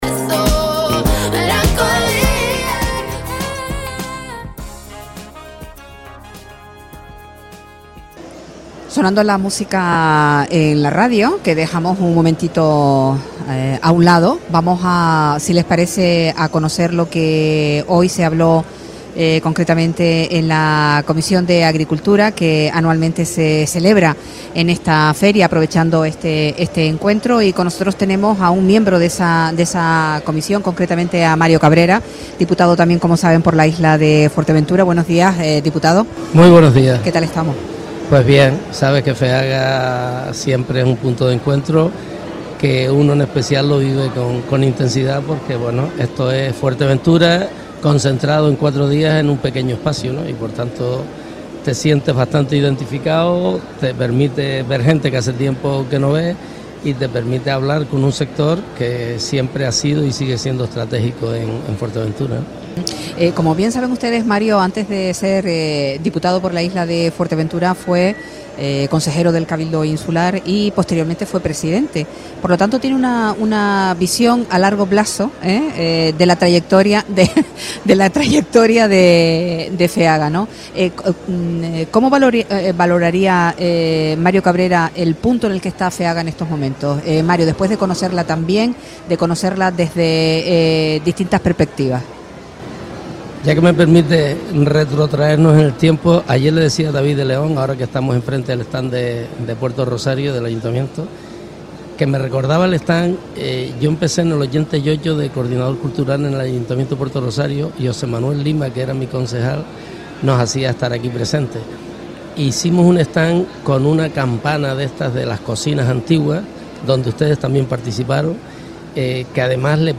El diputado por CC Mario Cabrera visita el set de Radio Sintonía en Feaga - Radio Sintonía
Entrevistas